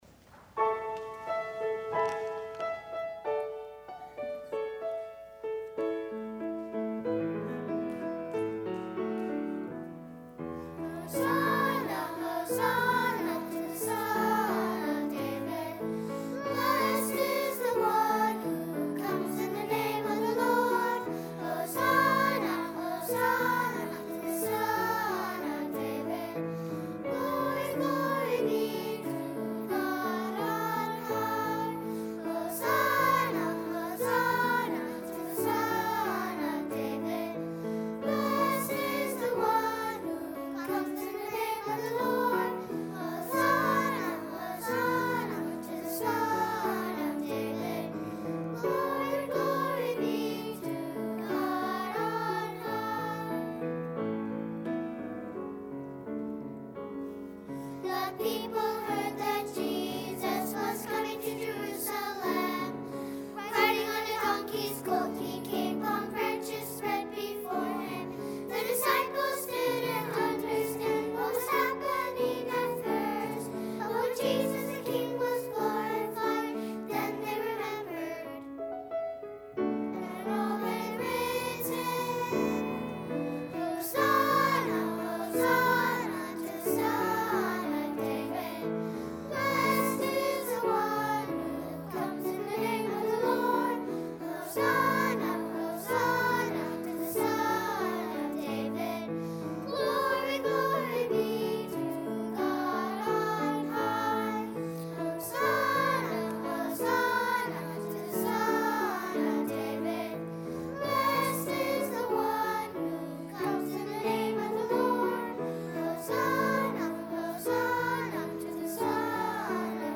Junior Choir
piano